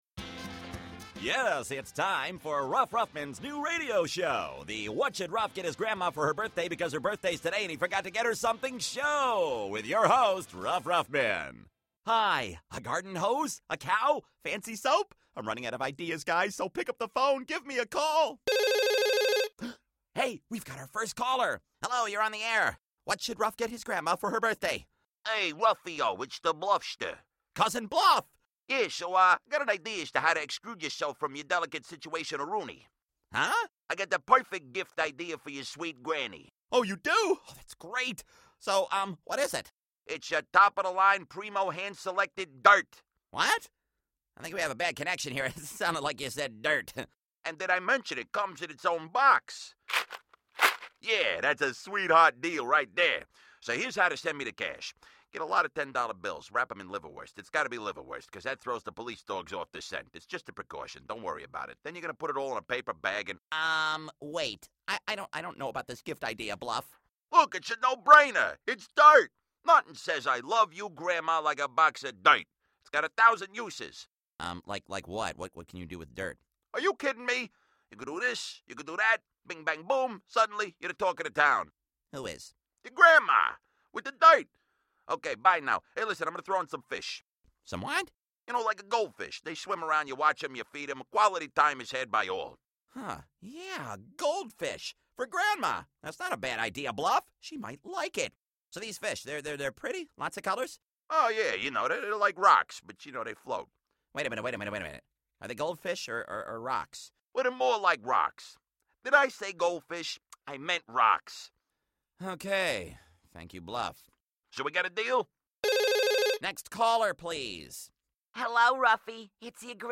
Ruff forgot to get Grandma Ruffman a birthday present, so he hosts his own radio talk show to ask for gift ideas. Ruff's cousin, Bluff, and his brother, Scruff, call the show and have some interesting birthday ideas.